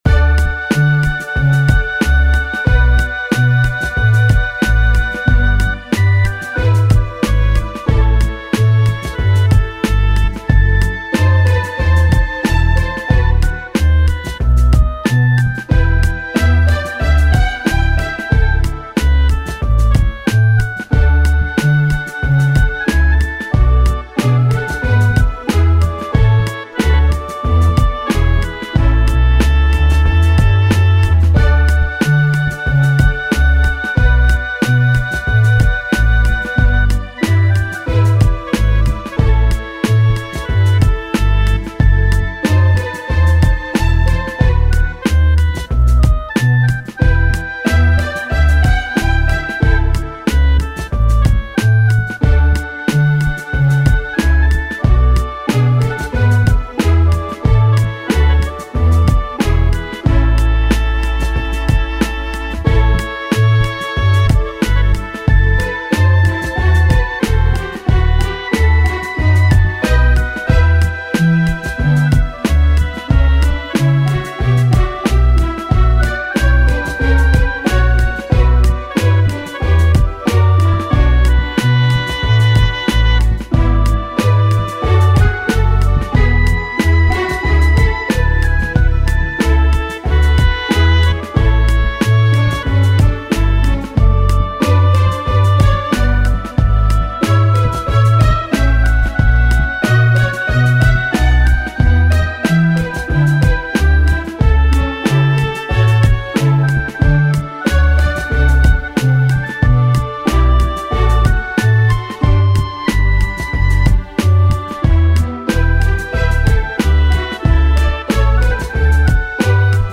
Orchestral